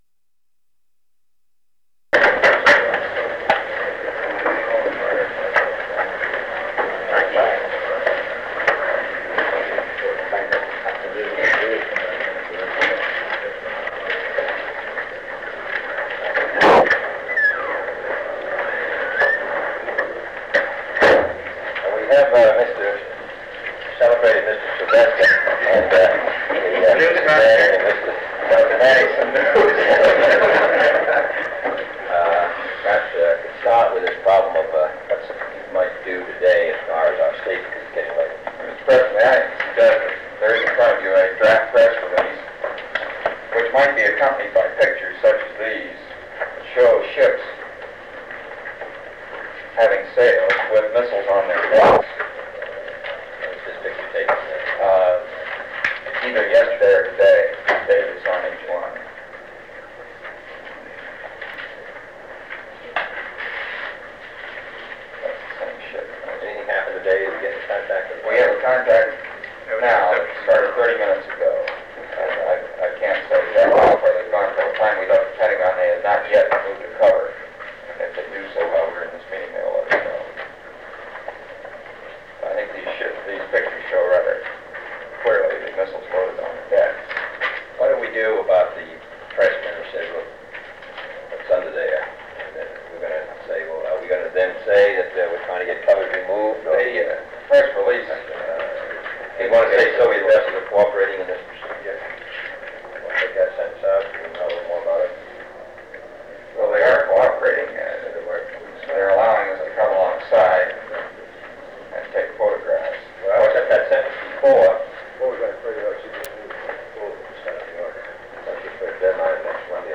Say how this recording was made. Secret White House Tapes | John F. Kennedy Presidency Executive Committee Meeting of the National Security Council Rewind 10 seconds Play/Pause Fast-forward 10 seconds 0:00 Download audio Previous Meetings: Tape 121/A57.